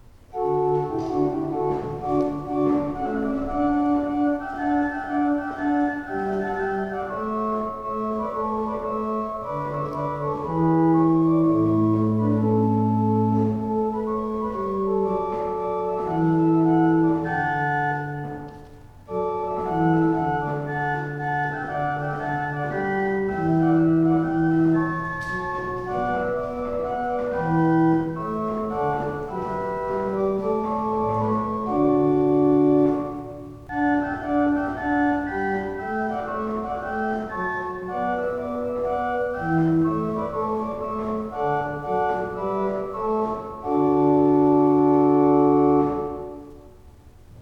Listen to an improvisation on the Gedackt 8' and Flaute 4' by clicking
Gedackt_8_Flaute_4.wav